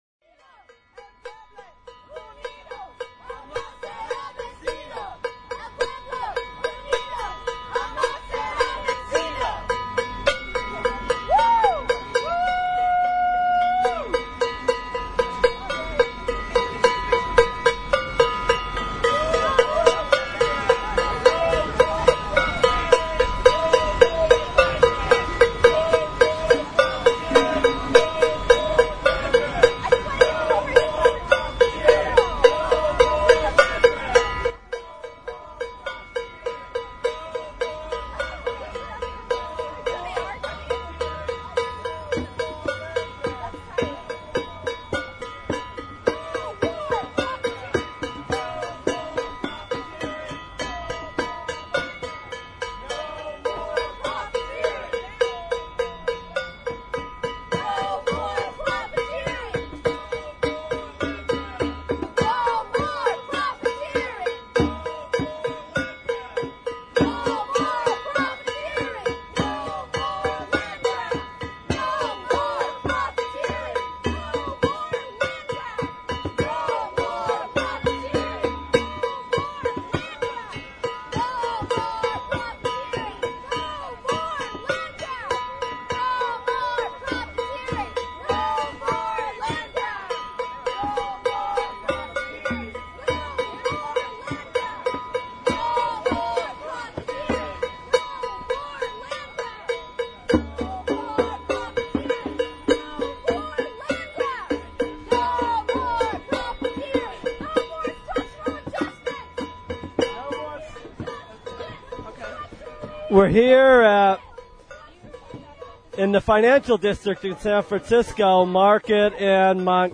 A spirited rally and speakout broke out in the Financial District as upwards towards 100 people rallied at Montgomery BART on Friday, October 19 during the afternoon commute hour. IMF policies that impoverish indigenous peoples in the Global South were denounced.
Hear 18 minutes of audio from the rally.